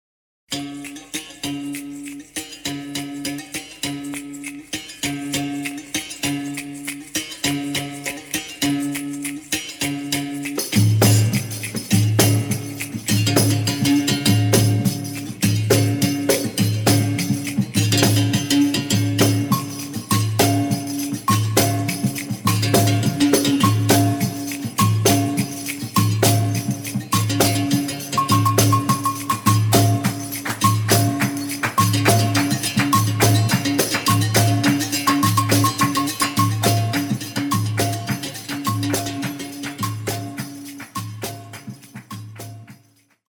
library music collection